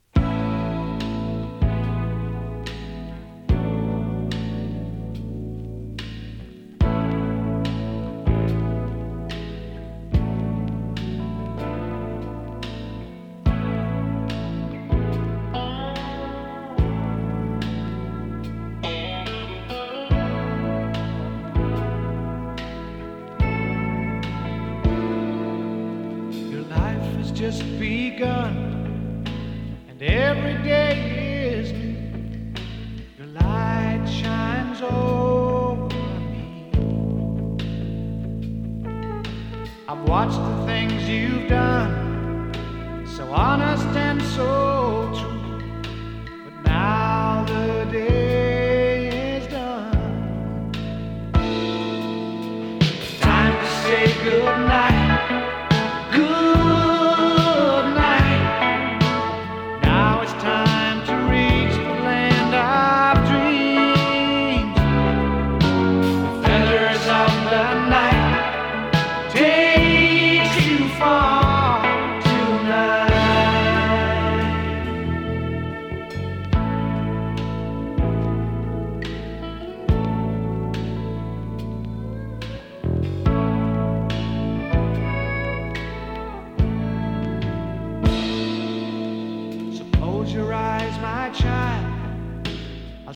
北欧産AOR